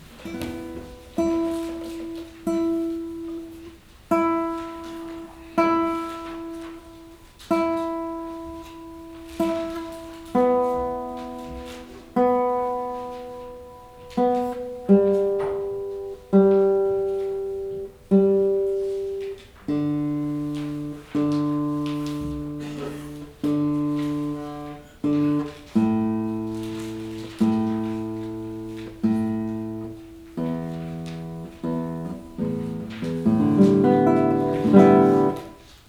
客席前にマイクを設置。
H2essentialのマイクは、フロントで指向性は90°。
ギターの調弦を比較用に切り出しました。
H2essential 指向性90°を、 サウンドフォージでノーマライズしました。
H2e_guitar.wav